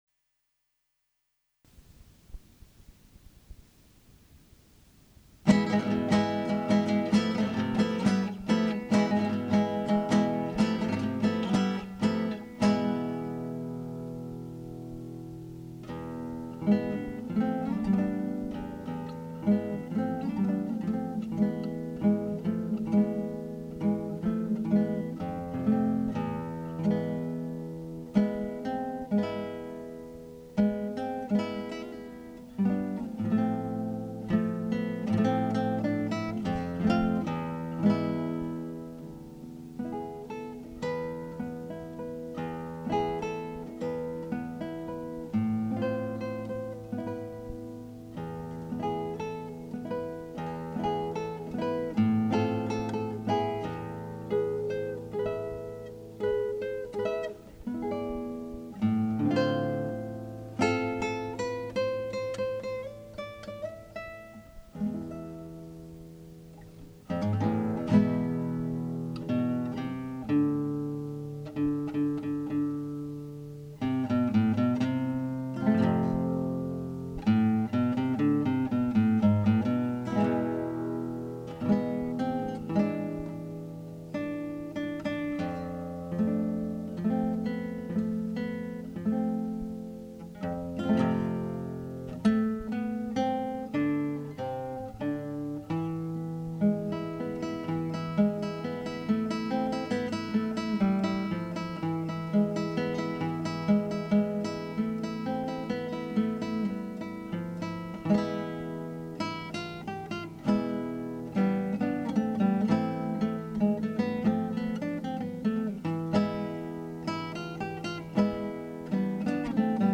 This guitarist performs the standards for your wedding or social event on the classical acoustic guitar, and can play subtle "jazz" electric guitar for your cocktail hour or other intimate gathering.
guitar